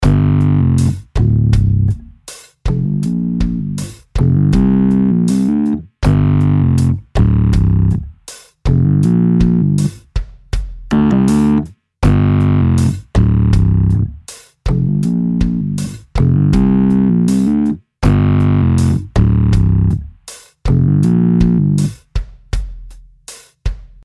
Dosierbare Geräusche der Mechanik fügen Patina und Akzente hinzu.
Bei hartem Anschlag wird der Klang leicht angezerrt und wirkt markant.
Rockiger wird es, wenn man den eingebauten Verzerrer einschaltet, der gleich verschiedene Charakteristika, Drive und Tone mitbringt.